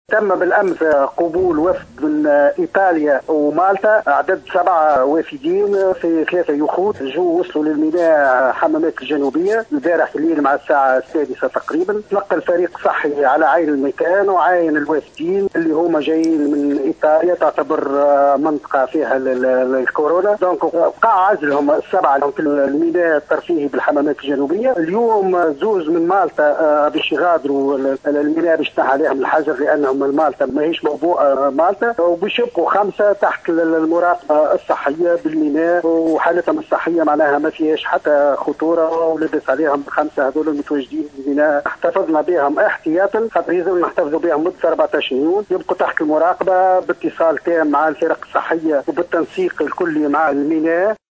أكد المدير الجهوي للصحة بنابل، عادل الحدادي في تصريح اليوم لمراسلة "الجوهرة أف أم" أنه تم إخضاع 7 أشخاص (ايطاليين وافارقة) وصولوا مساء أمس من إيطاليا ومالطا إلى ميناء ياسمين الحمامات عبر 3 يخوت، إلى الحجر الصحي.